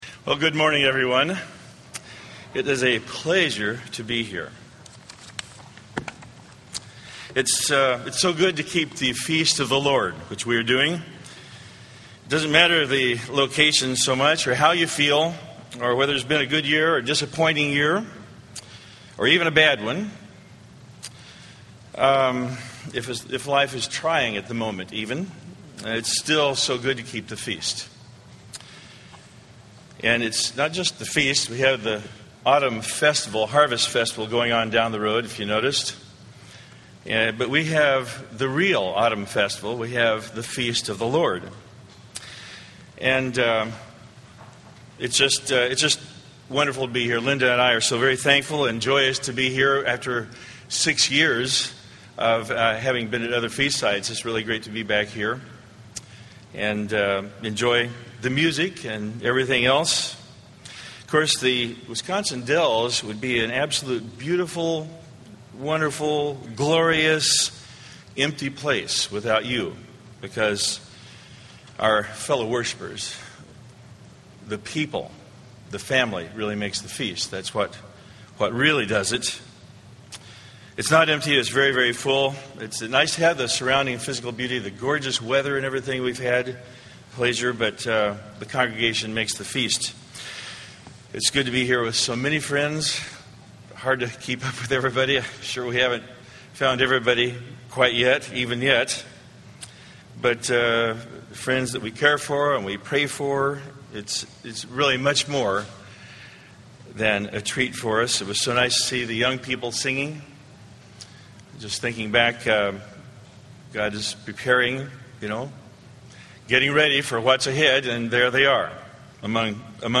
This sermon was given at the Wisconsin Dells, Wisconsin 2008 Feast site.